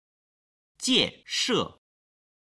今日の振り返り！中国語発声
01-jianshe.mp3